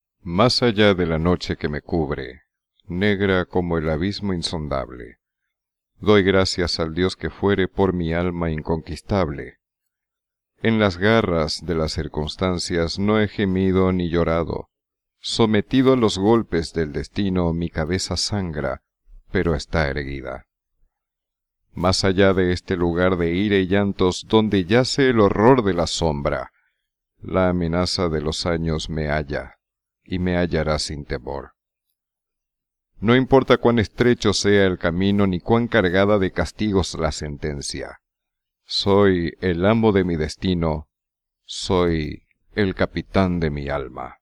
Suave, profunda y sugestiva